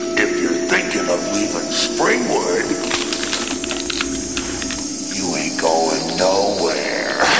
FREDDY KRUEGER- ROBERT ENGLUND